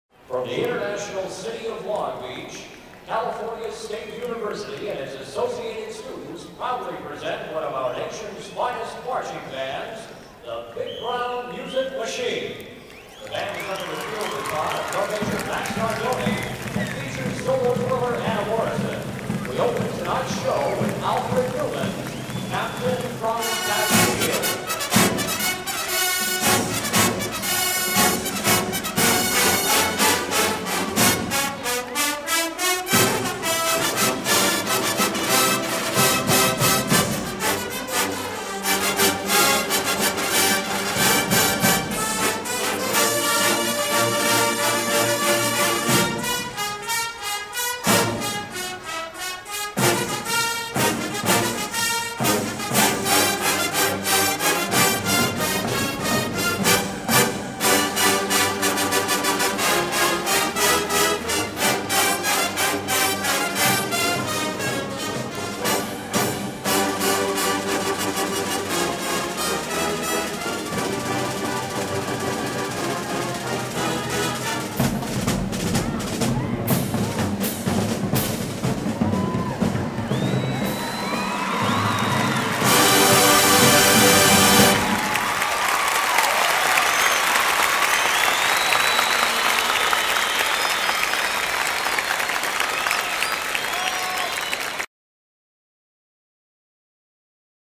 Live performance 1979